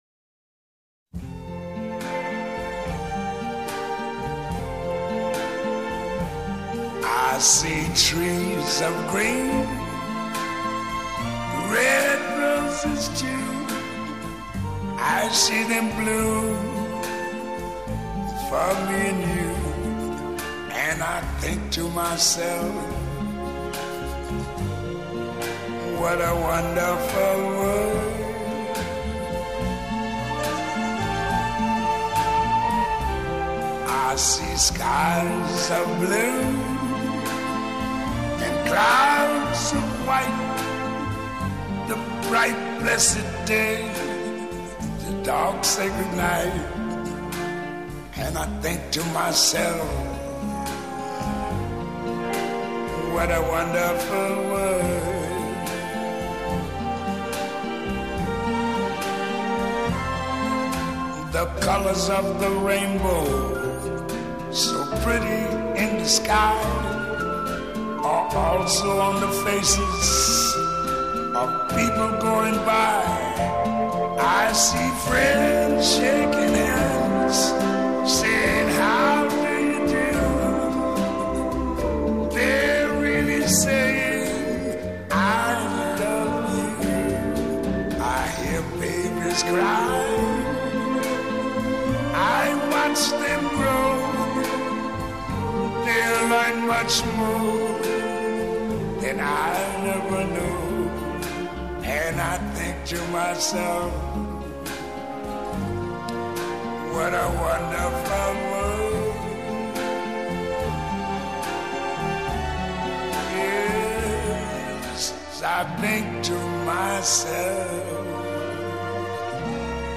大师独特沙哑的嗓音让人听过以后印象非常深刻。